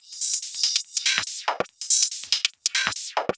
Index of /musicradar/uk-garage-samples/142bpm Lines n Loops/Beats
GA_BeatAFilter142-02.wav